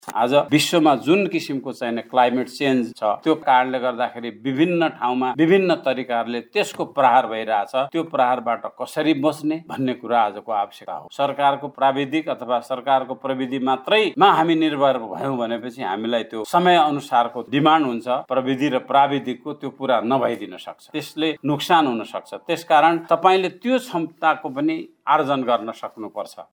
बुधवार सुर्खेतमा आयोजित ‘कर्णाली प्रदेशमा सामुदायिक बिउ बैंक ः वर्तमान अवस्था र विस्तारको आवश्यकता’ विषयक प्रदेशस्तरीय नीति संवाद कार्यक्रममा सहभागी सरोकारवालाहरूले सामुदायिक बिउ बैंक विस्तारलाई अपरिहार्य बताएका हुन् ।